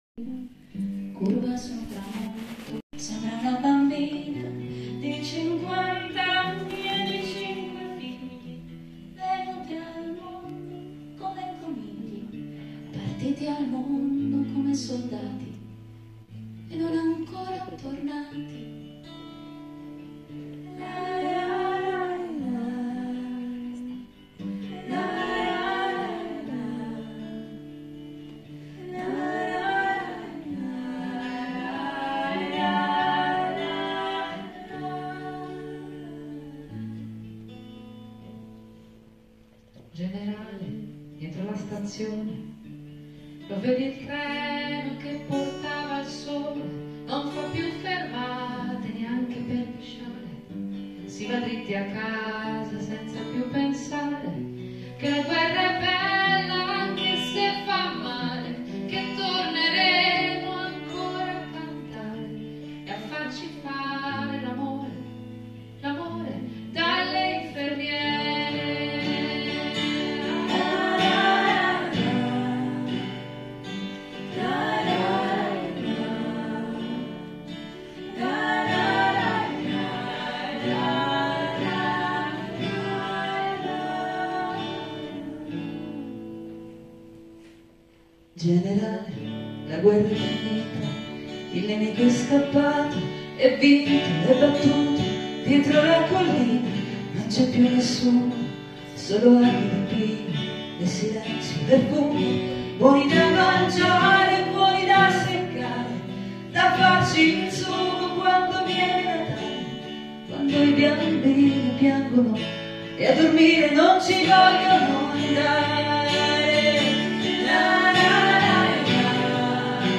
Concierto dedicado a la canción italiana.
Temazo sobrecogedor.